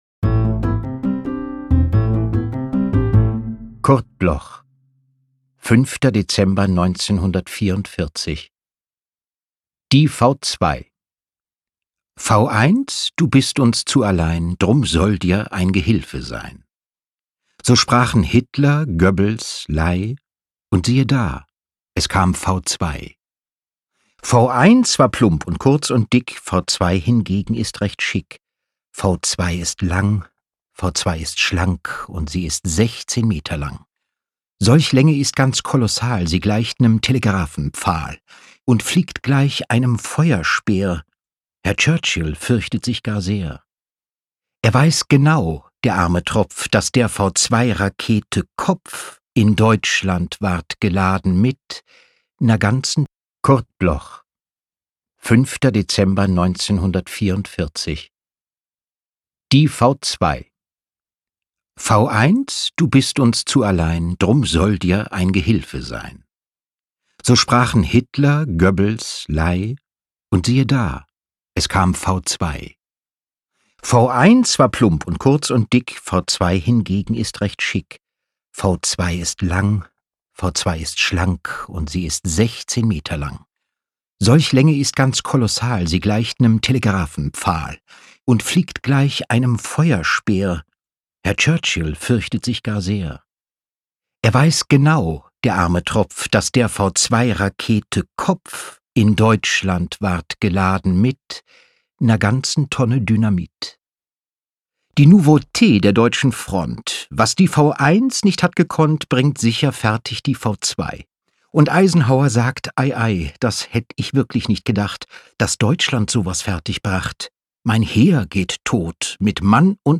Recording: Argon, Berlin · Editing: Kristen & Schmidt, Wiesbaden
Sebastian-Blomberg-Die-V2_raw-mit-Musik.m4a